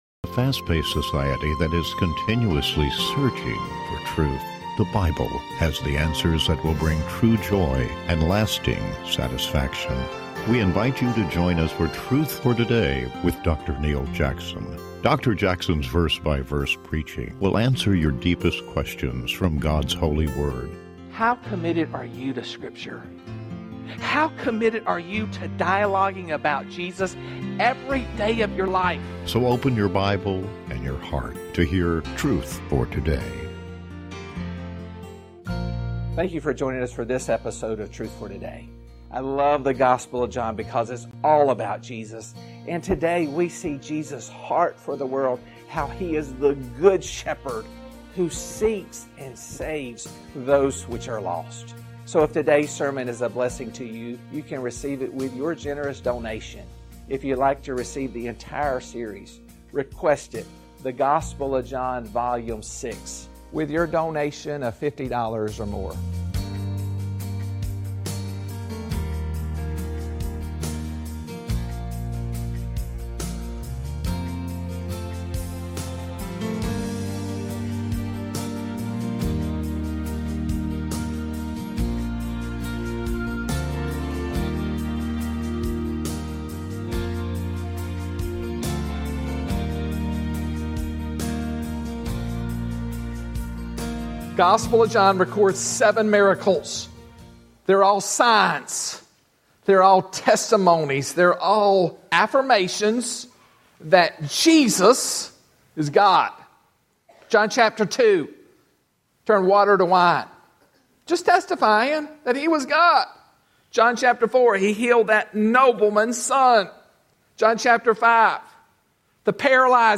Talk Show Episode, Audio Podcast, Truth For Today and The Day Jesus Played in the Mud on , show guests , about The Day Jesus Played in the Mud, categorized as Health & Lifestyle,History,News,Politics & Government,Religion,Society and Culture